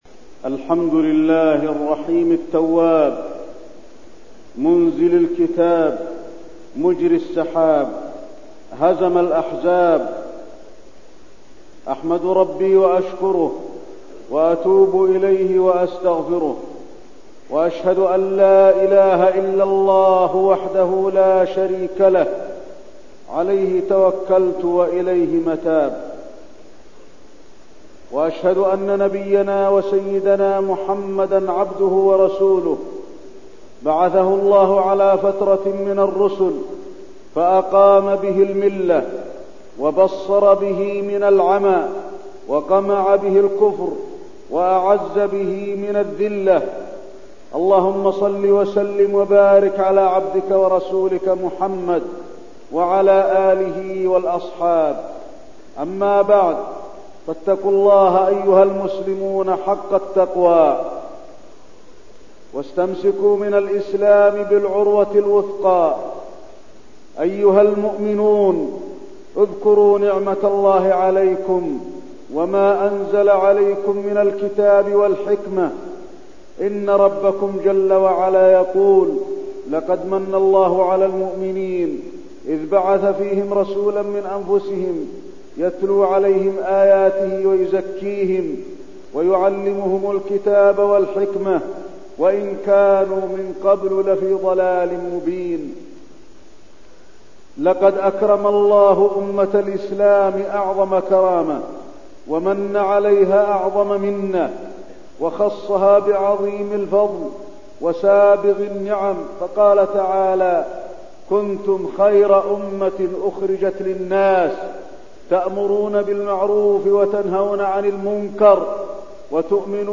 تاريخ النشر ٢٥ ذو الحجة ١٤١٢ هـ المكان: المسجد النبوي الشيخ: فضيلة الشيخ د. علي بن عبدالرحمن الحذيفي فضيلة الشيخ د. علي بن عبدالرحمن الحذيفي من خصائص هذه الأمة